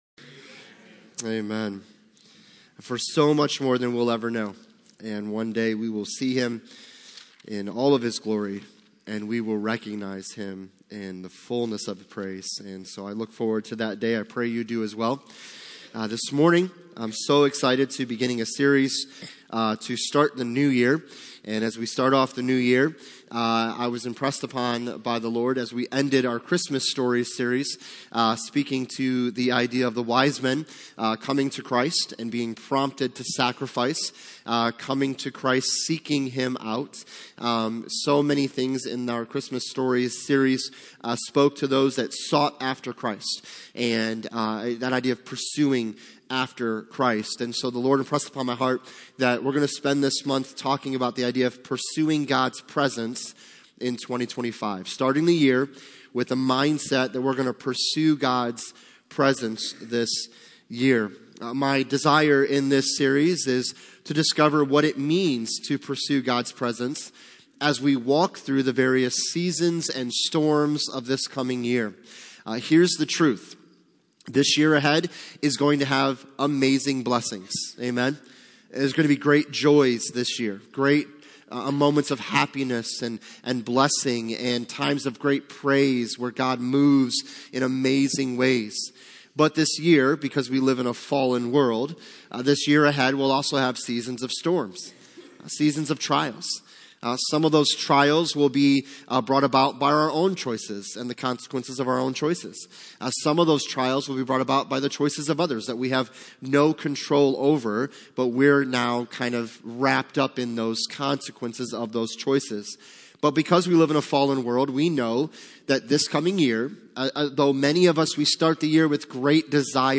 Passage: Exodus 33:13-16 Service Type: Sunday Morning